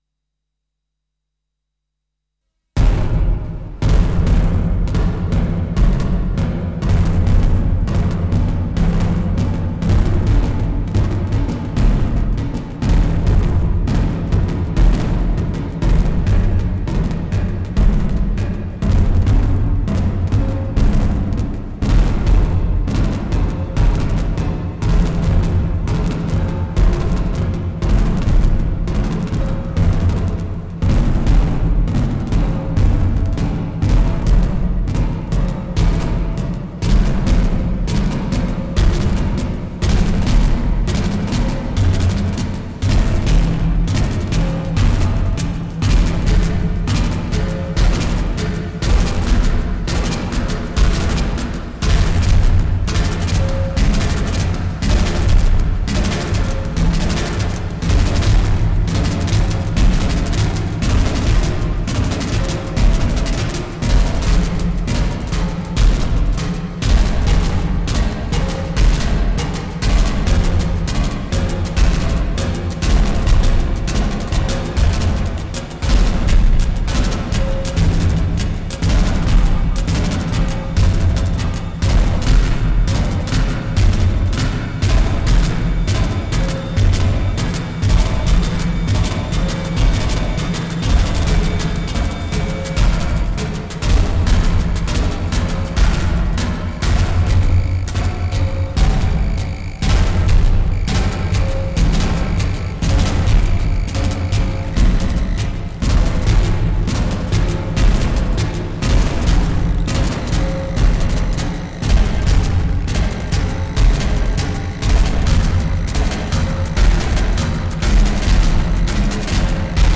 DFAM, Lyra, Acidbox and Quadraverb.